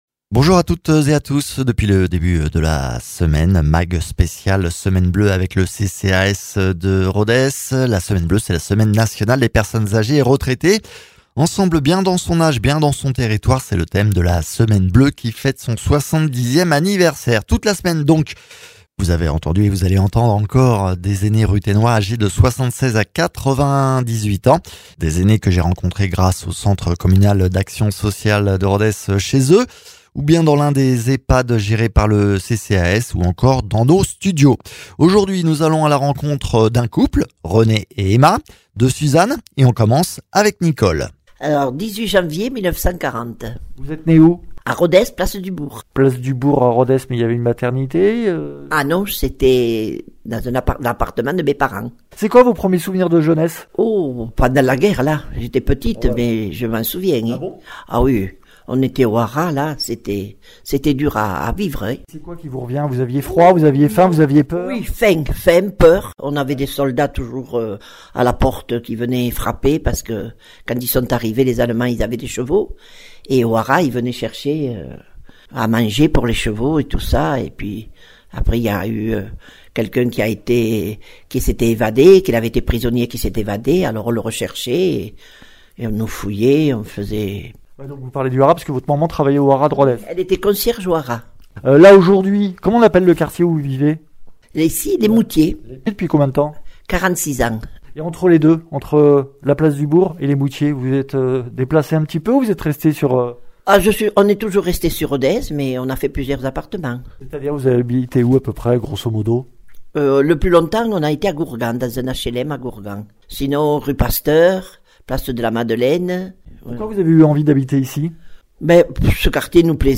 Dans le cadre de la semaine bleue, semaine nationale des personnes agées et retraités et en partenariat avec le CCAS de Rodez, des séniors ruthénois reviennent sur leur vie à Rodez et en Aveyron.